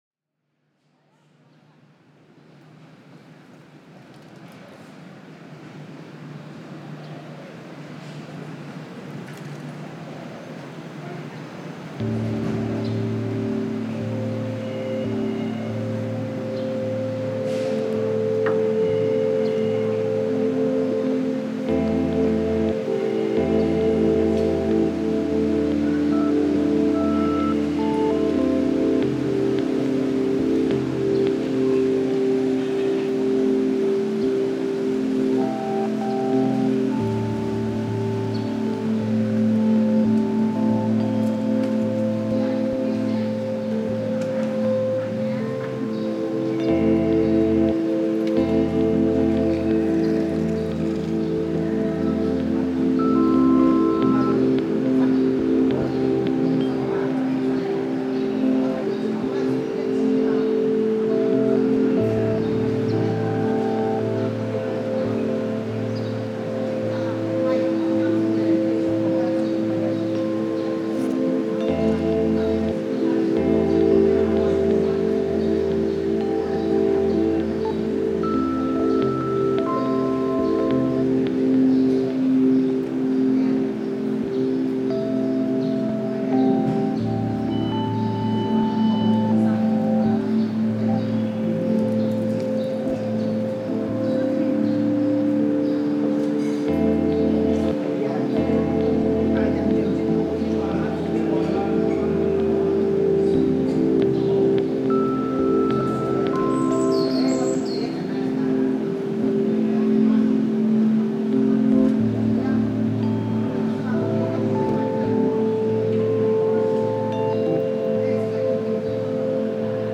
Genre: Relax, Meditation, Ambient, New Age, Ambient.